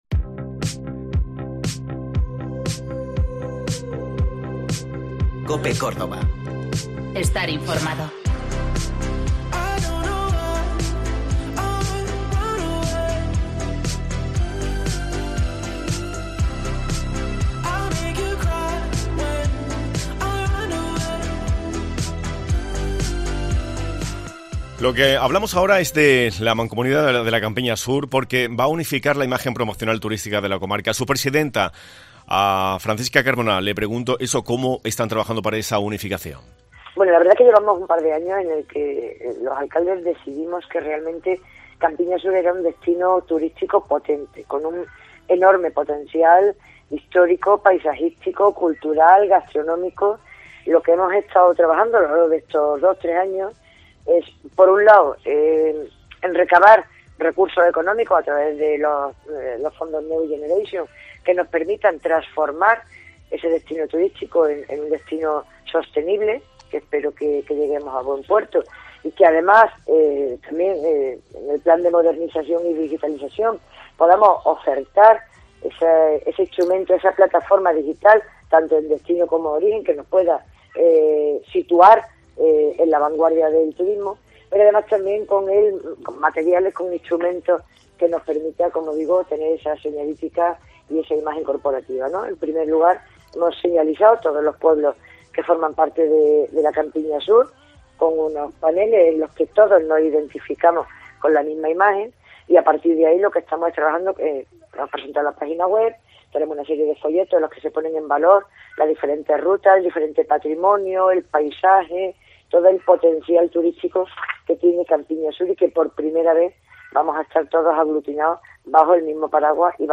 La presidenta de Campiña Sur y alcaldesa de Moriles nos ha detallado además qué actividades encontramos por las bodegas de su localidad